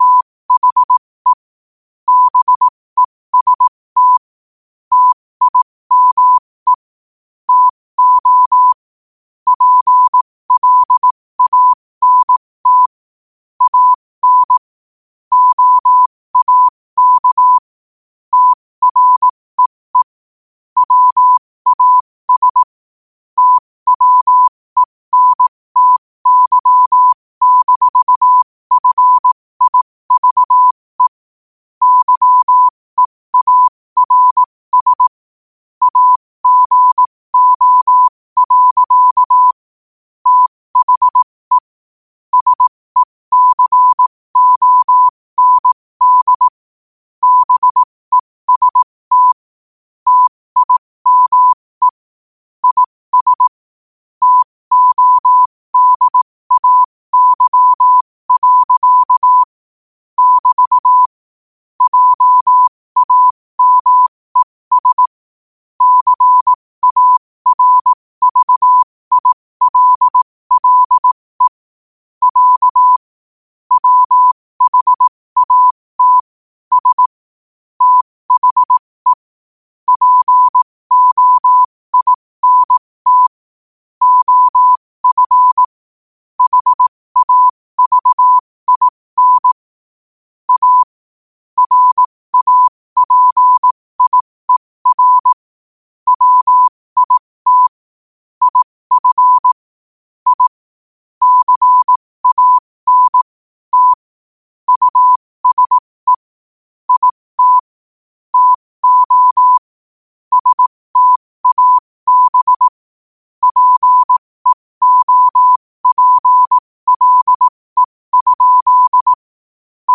Quotes for Wed, 01 Oct 2025 in Morse Code at 15 words per minute.